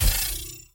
blockDestroy.ogg